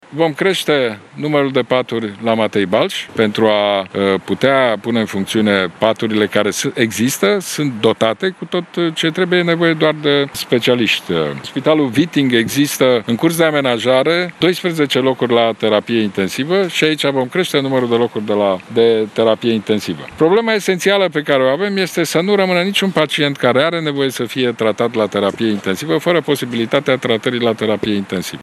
Locurile la Terapie Intensivă vor fi suplimentate, atât în București, cât și în alte zone din țară, a anunțat luni premierul Ludovic Orban: